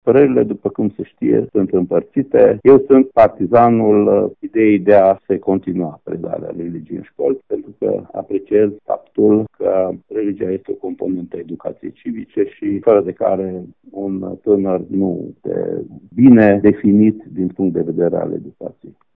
Şi inspectorul şcolar general Ştefan Someşan susţine necesitatea religiei în şcoli.